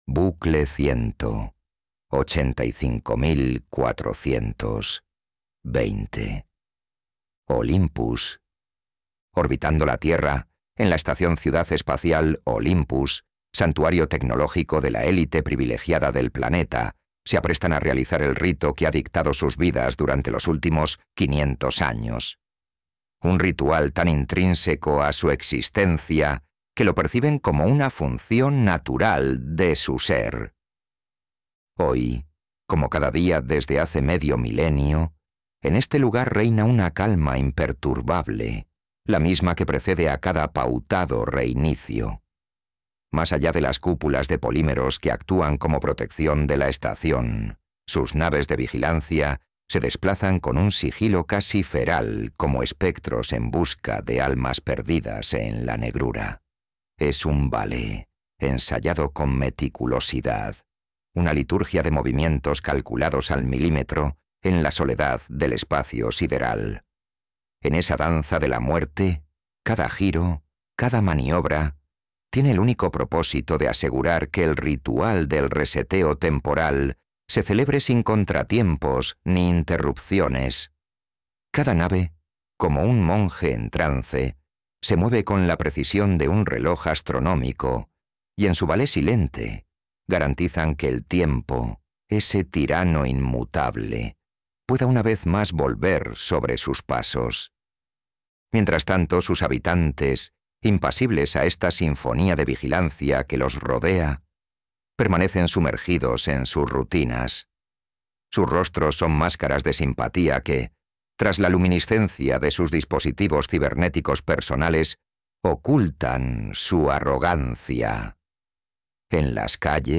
Ebook y Kindle Unlimited Audiolibro en castellano en las mejores plataformas Imagina un mundo en el que cada día comienza de nuevo, igualito al anterior, como si alguien hubiese puesto el tiempo en pausa.